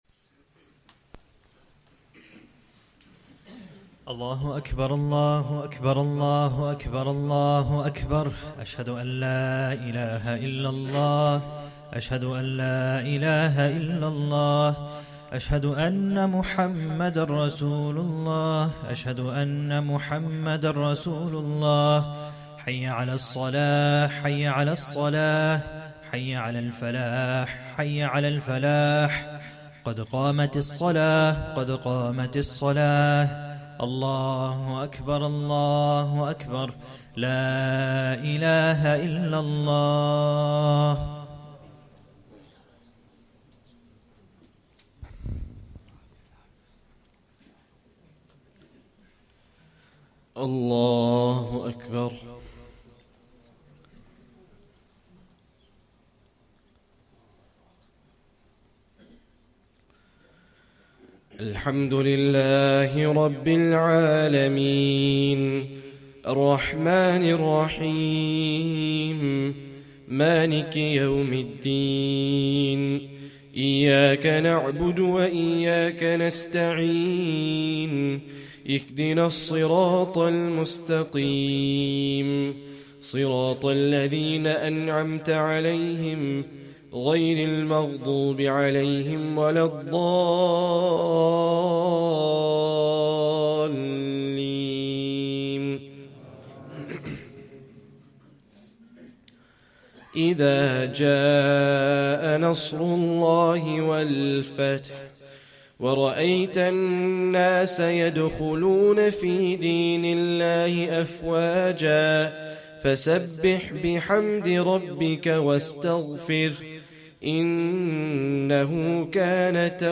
1st Tarawih prayer -4th Ramadan 2024 (1st portion)